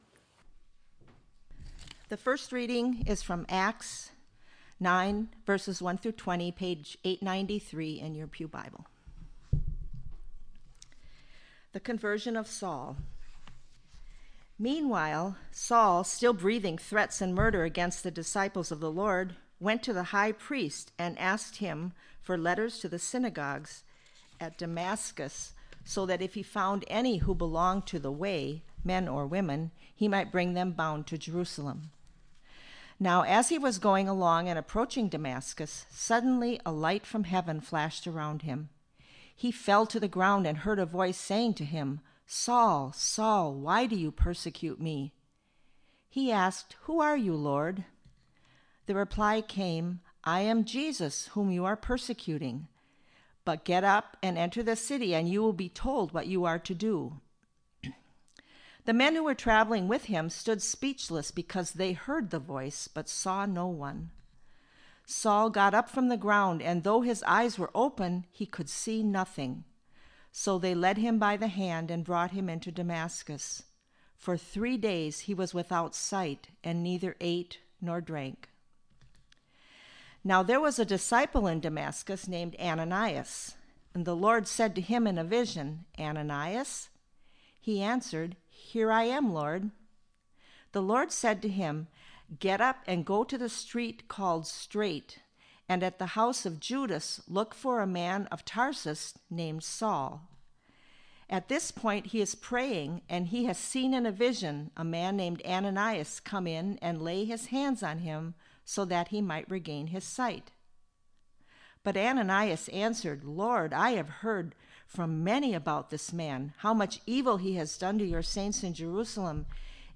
sermon-3.mp3